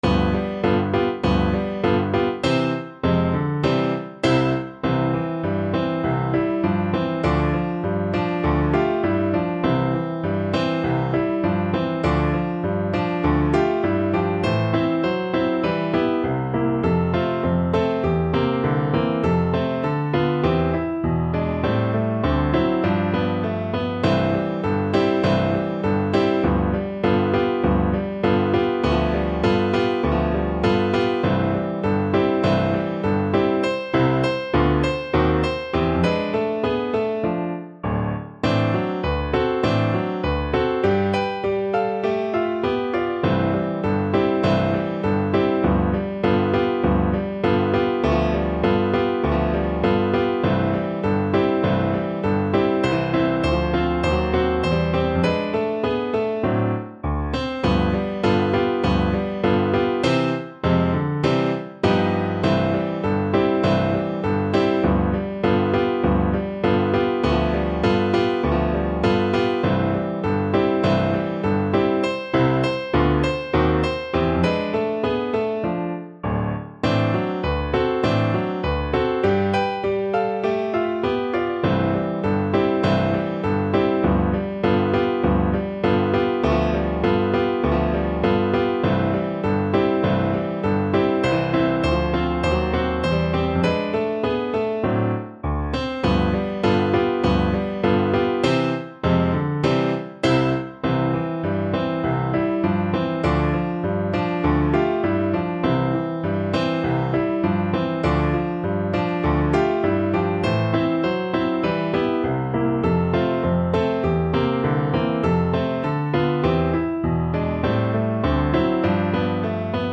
Moderato =c.100
Pop (View more Pop Voice Music)